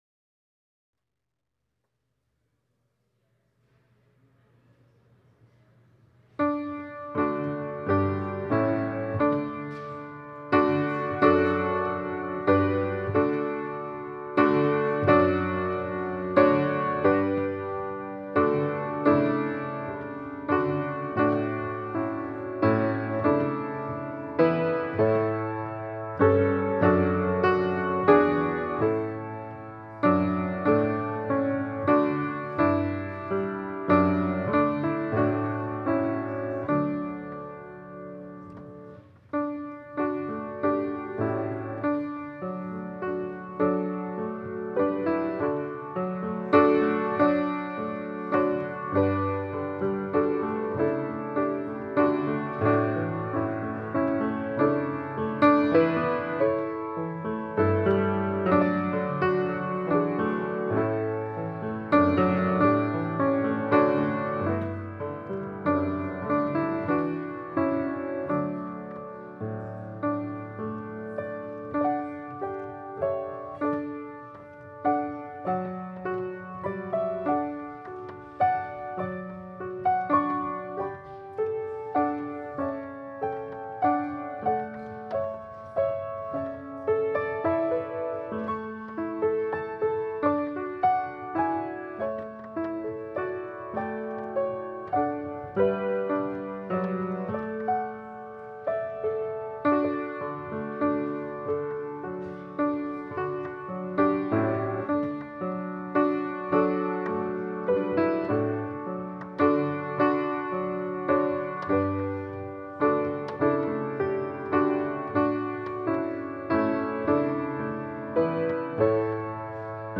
Passage: Psalm 117 Service Type: Special Service Scriptures and sermon from St. John’s Presbyterian Church on Sunday